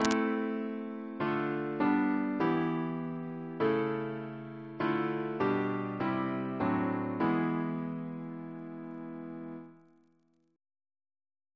Single chant in A minor Composer: Bryan Hesford (1930-1996) Reference psalters: ACP: 301